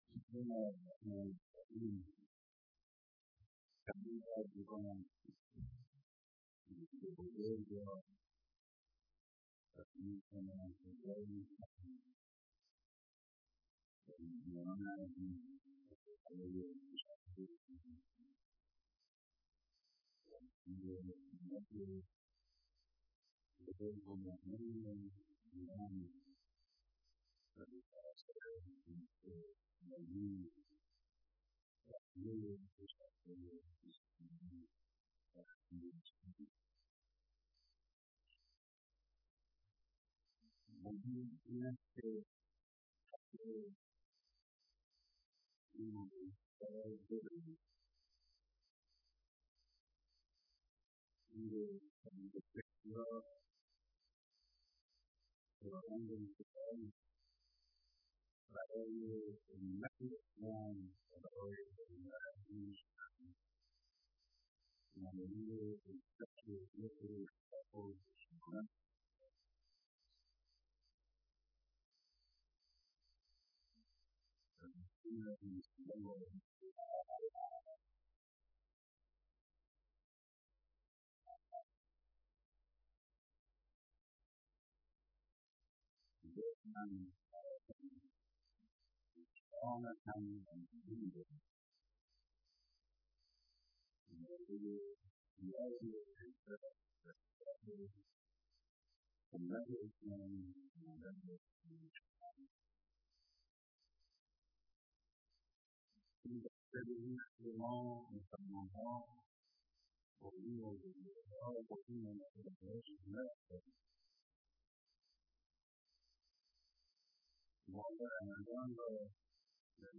بیانات در ديدار مسئولان نظام و سفرای كشورهای اسلامی